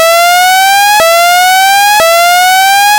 13000〜17000回転でシフトアップする音を作ってみる。
こんな音になった。カン高いフェラーリサウンドの片鱗を感じられなくもないが、コレジャナイ。主な理由は音の成分が単一だからだ。
12kitou_f1_shift3.mp3